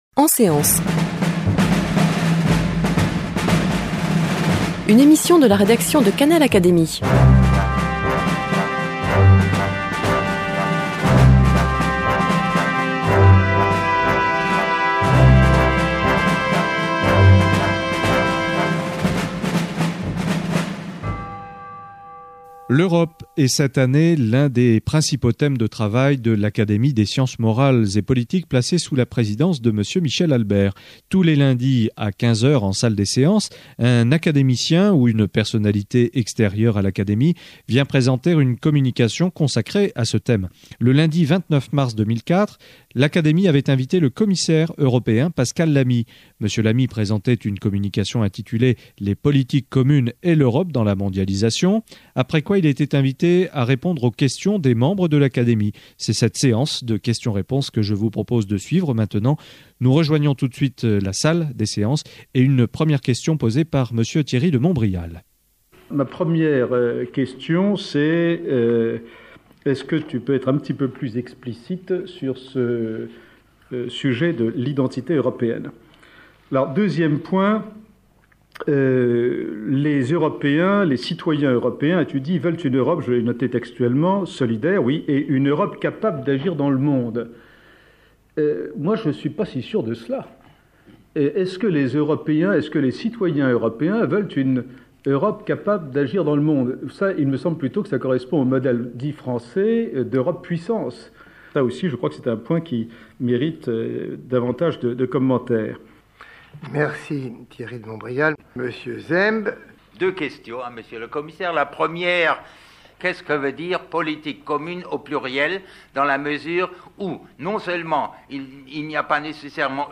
Pascal Lamy, commissaire européen, répond aux Académiciens après sa communication.